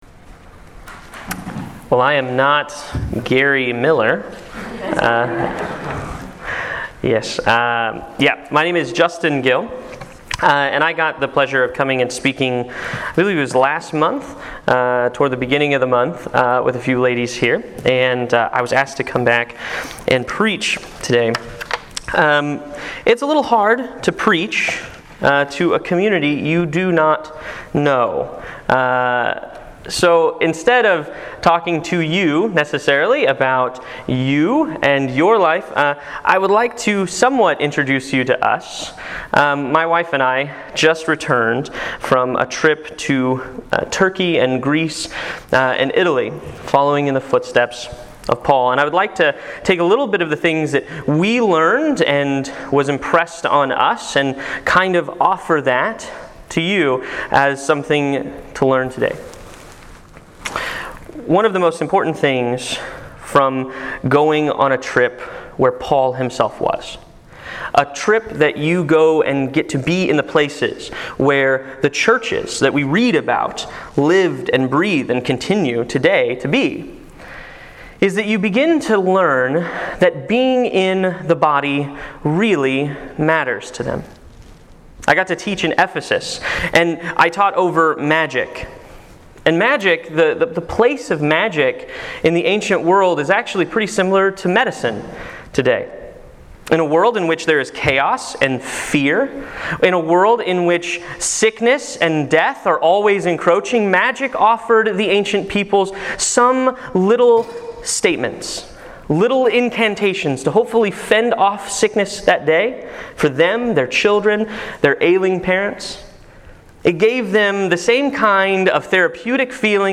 The Sunday after the Fourth of July I had been asked to speak at a small church in rural Illinois.